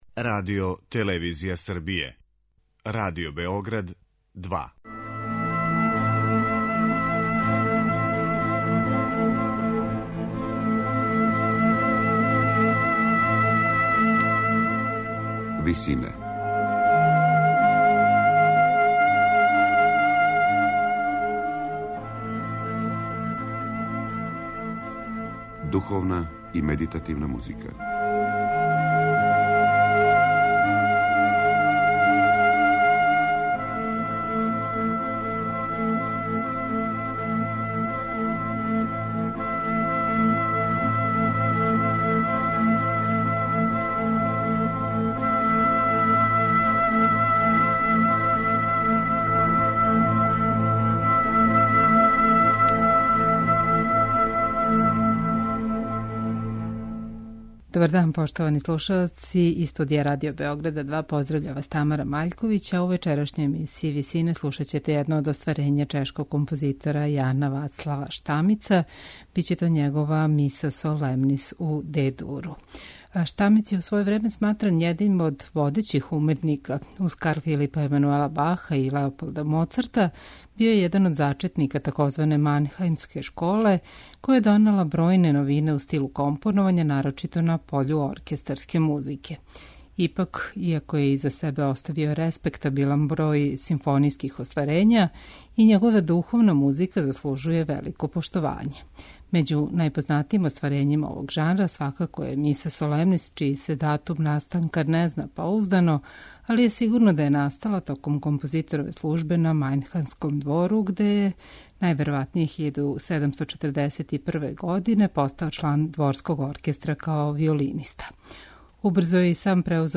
Међу најпознатијим остварењима овог жанра свакако је Миса солемнис у Д-дуру за солисте, хор и оргуље, коју вам представљамо у емисији Висине.